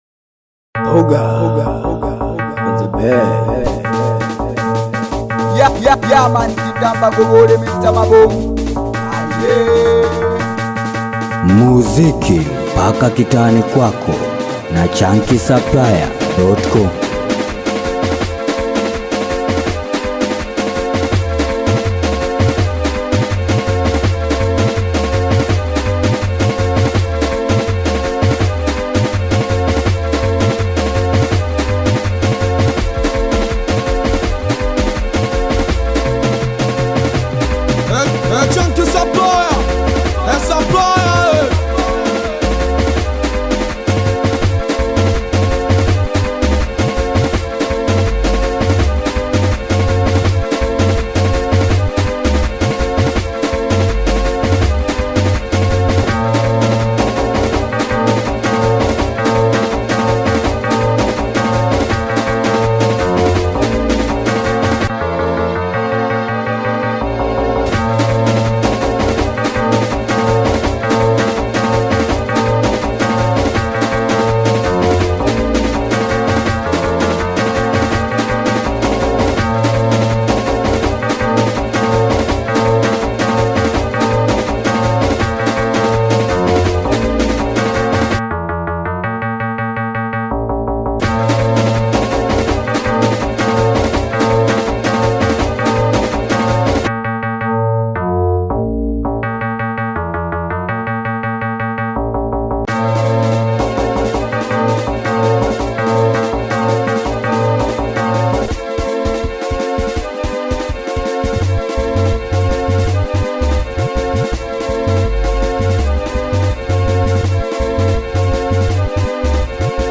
SINGELI BEAT